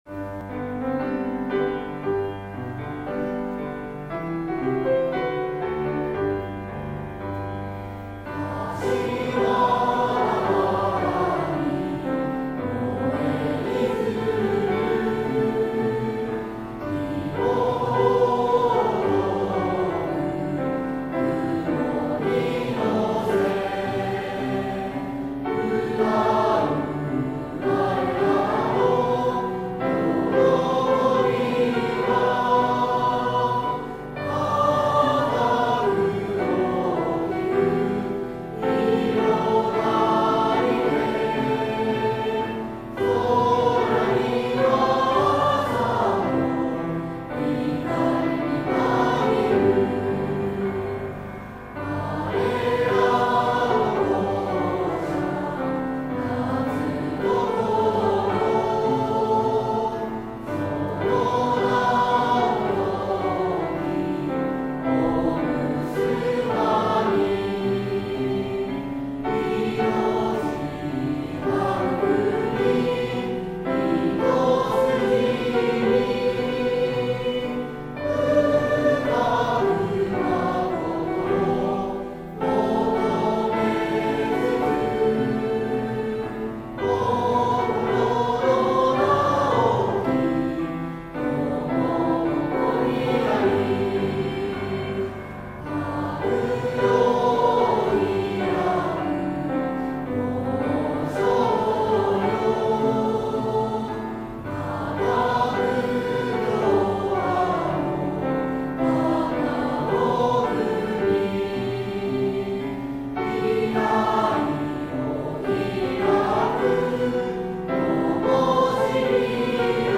第７４期卒業生のみなさんが素敵な歌声を残してくれました。